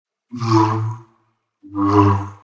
Download Lightsaber sound effect for free.
Lightsaber